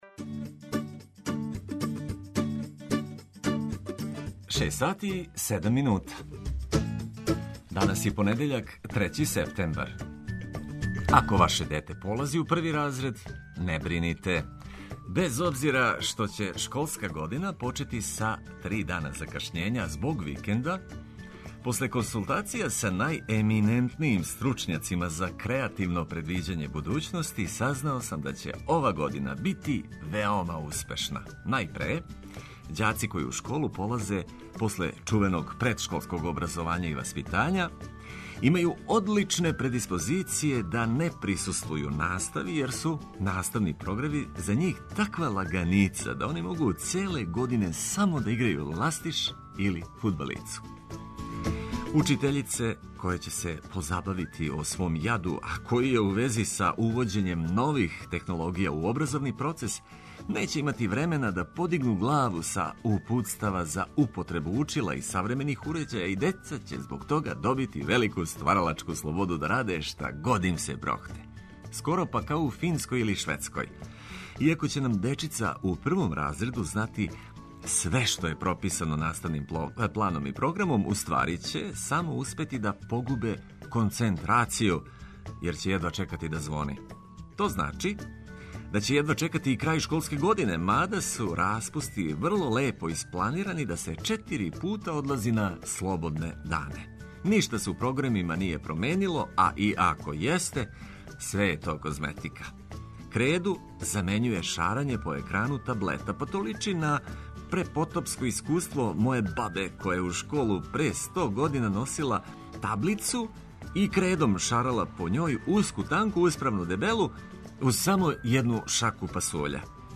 Спремна је и музика која тера из кревета, а на вама је само да нам се придружите.